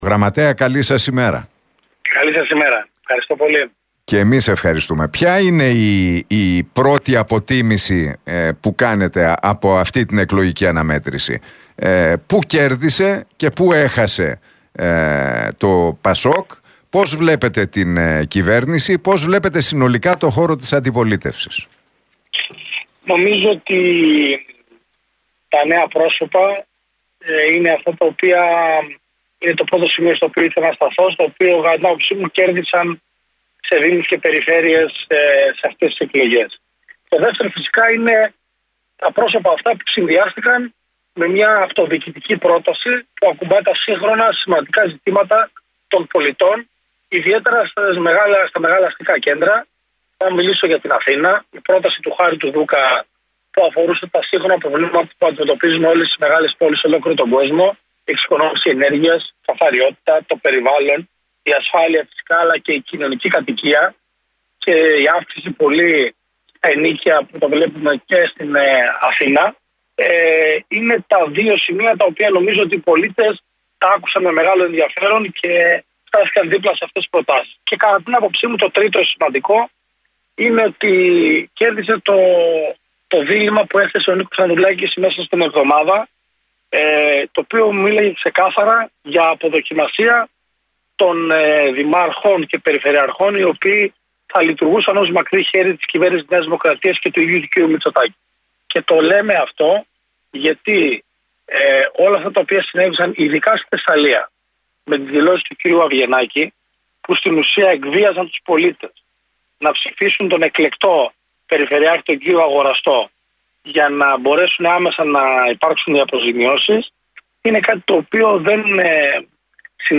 Ο Γραμματέας του ΠΑΣΟΚ – ΚΙΝΑΛ, Ανδρέας Σπυρόπουλος, μίλησε στην εκπομπή του Νίκου Χατζηνικολάου στον Realfm 97,8 για τα αποτελέσματα της κάλπης των αυτοδιοικητικών εκλογών.